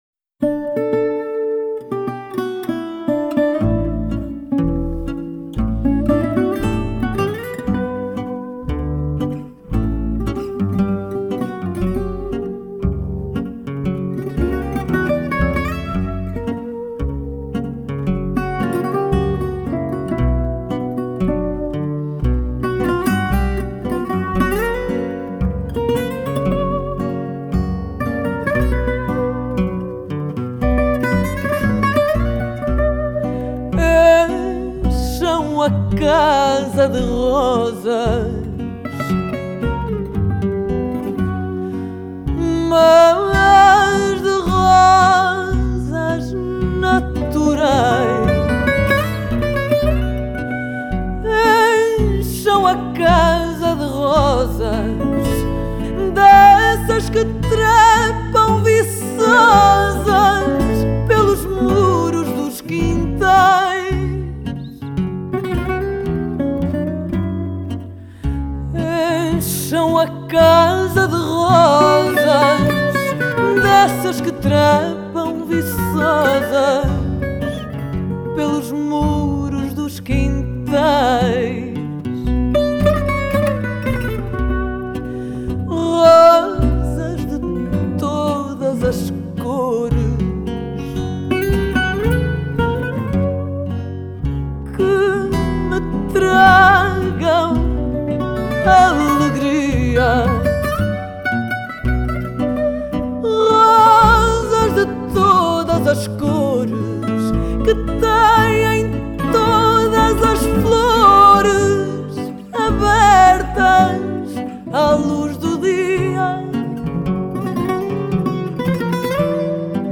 Genre: World, Fado